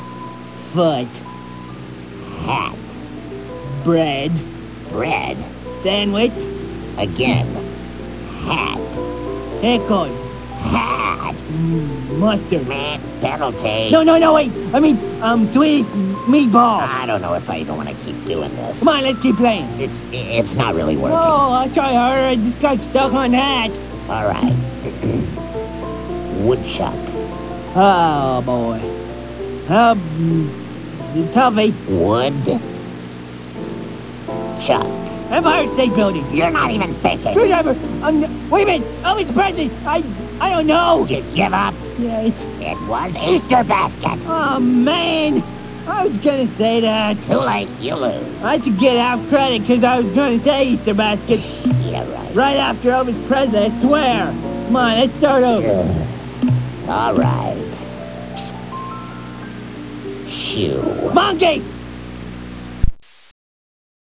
The stuff he says is great, and the way he says it is even better.
Brak and Zorack play some sort of word association game with Brak as his usual brilliant self!